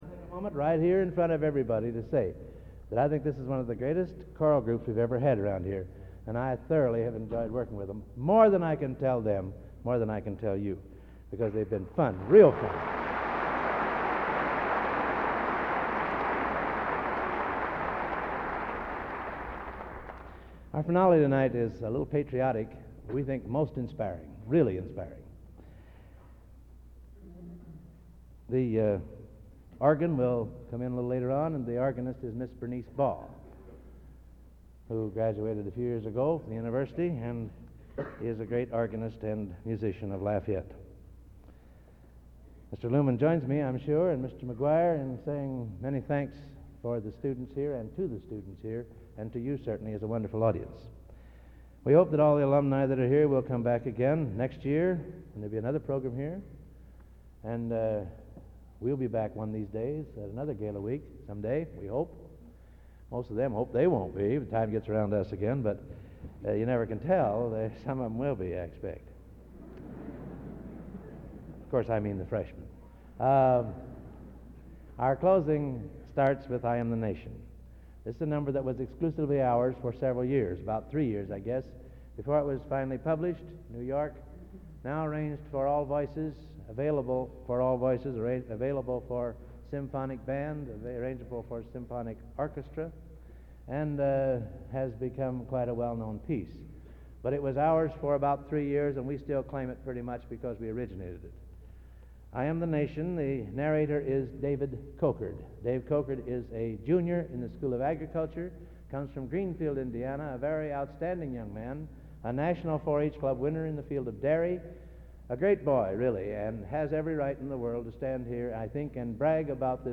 Collection: Gala Concert, 1961
Genre: | Type: Director intros, emceeing